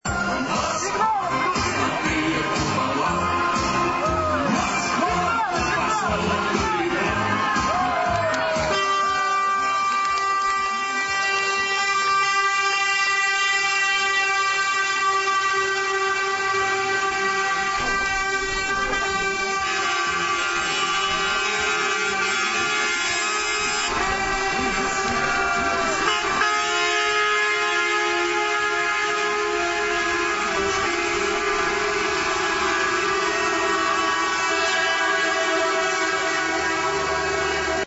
Грузовые автомобили поехали через мост — тоже традиция.
Послушать, как сигналили